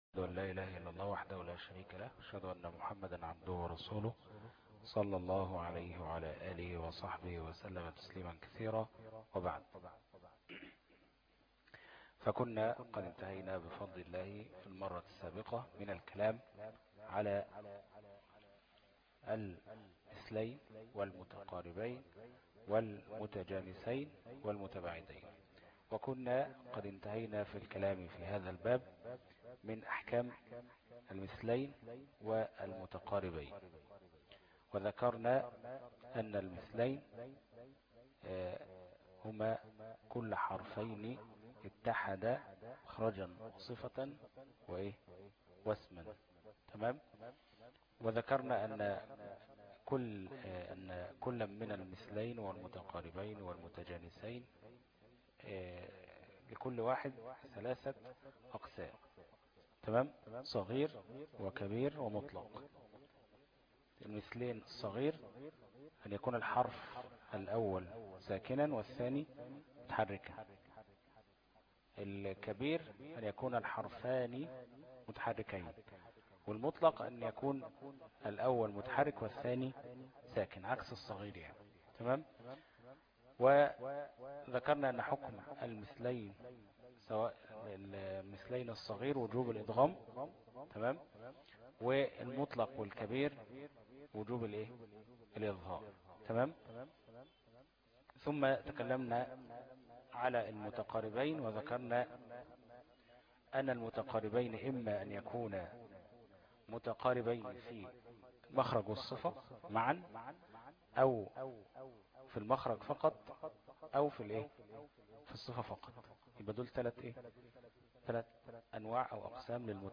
دروس التجويد 13 - الفرقة التمهيدية - الشيخ أبو إسحاق الحويني